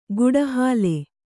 ♪ guḍa hāle